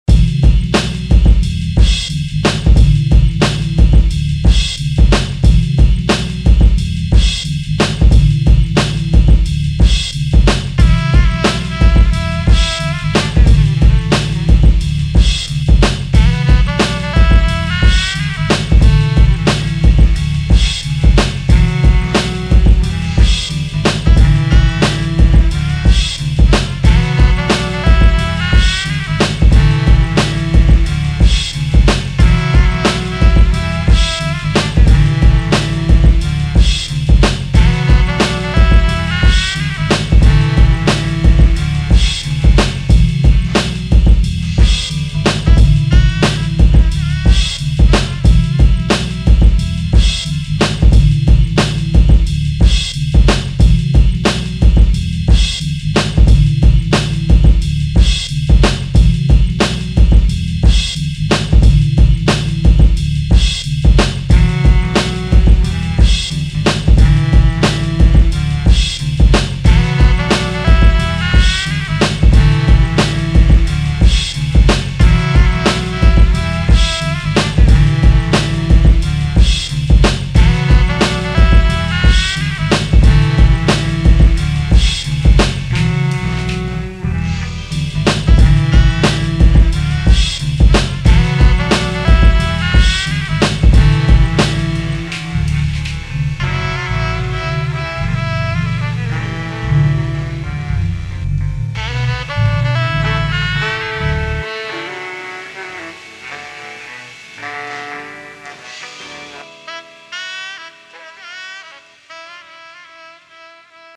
I only used the sample.
The filtered part sounds great on my laptop.
and your beat has a tasty jazzy element to it!